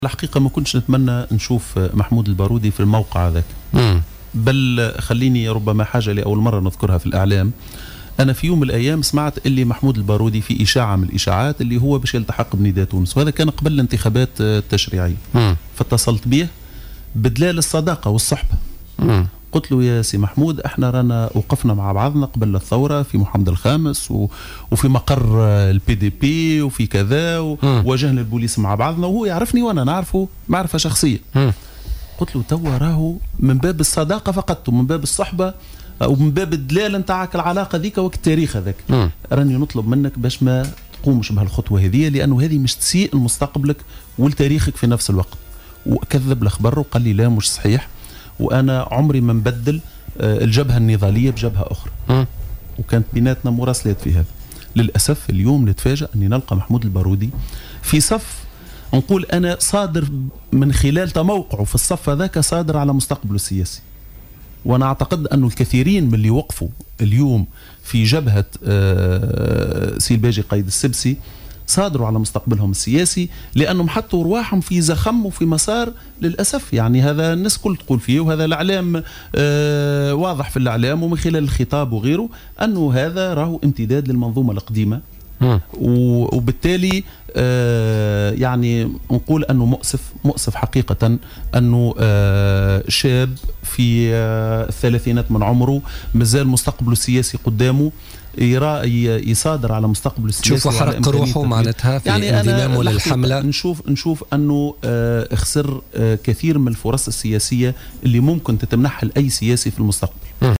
قال رياض الشعيبي أمين عام حزب البناء الوطني ضيف برنامج بوليتيكا اليوم الاربعاء...